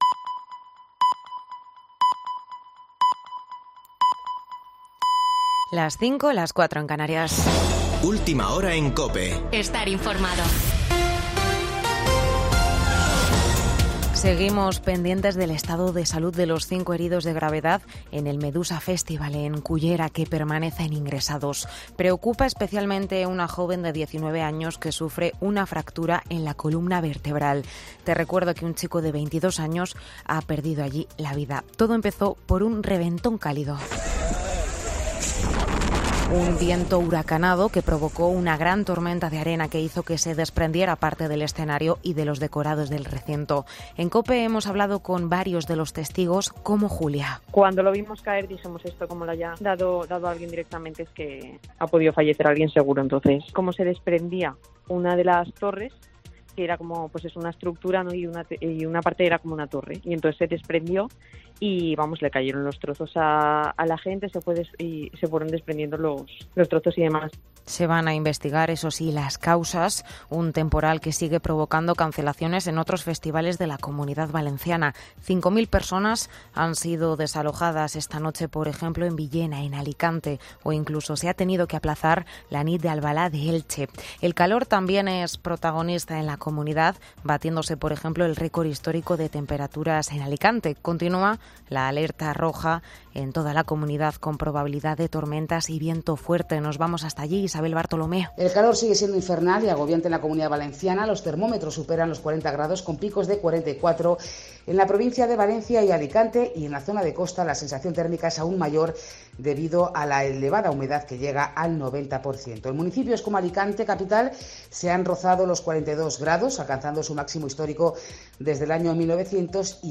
Boletín de noticias de COPE del 14 de agosto de 2022 a las 05.00 horas